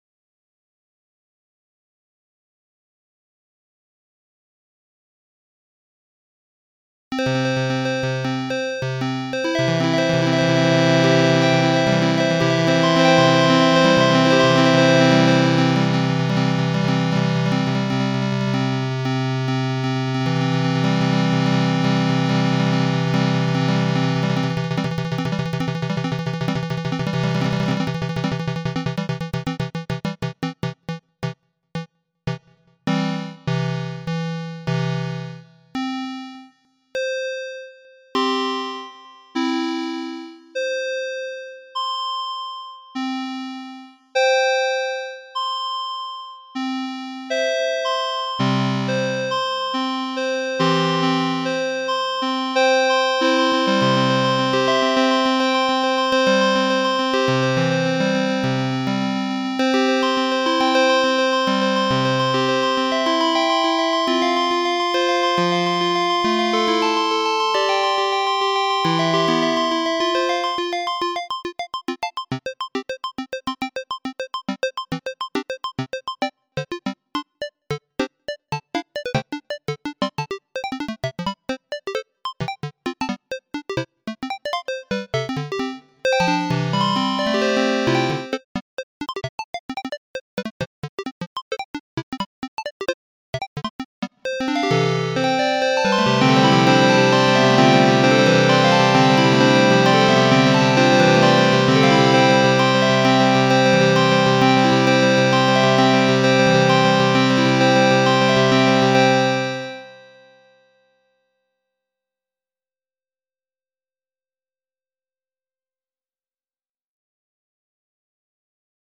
Built from a custom control surface, 24 incandescent bulbs and multi-channel speaker system, the work centres on eight algorithmically generated bouncing balls that trigger distinct pitches and lights as they strike the ground.